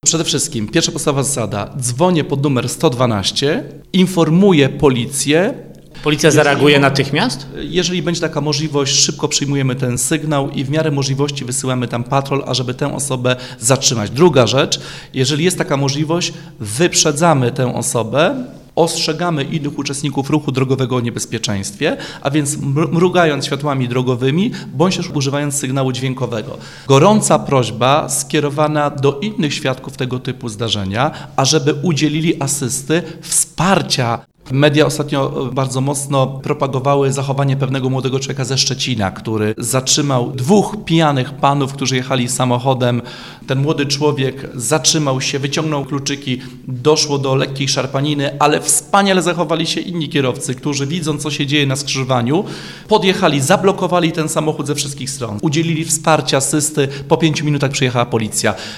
To wyjaśnia ponownie funkcjonariusz policji.